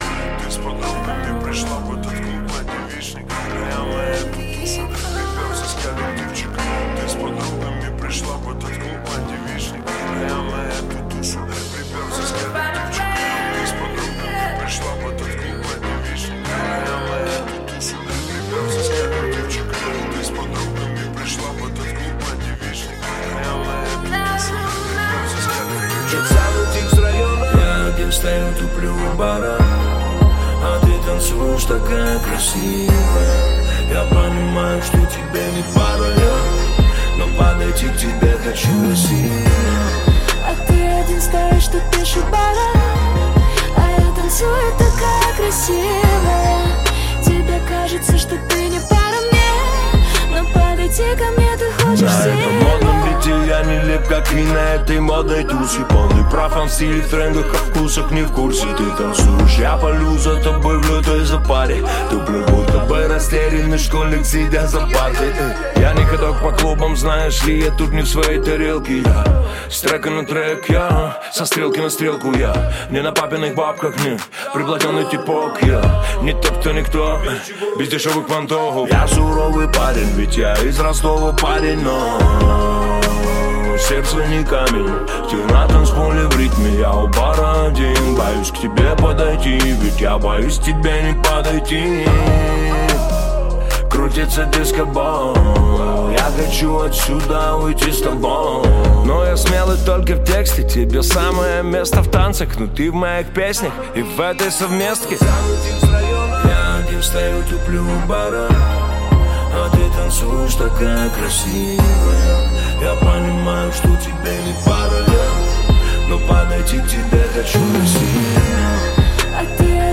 Русский рэп
Жанр: Русский рэп / R & B